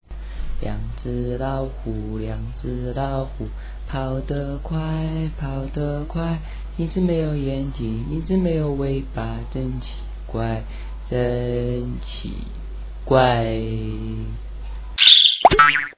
亲自唱的，，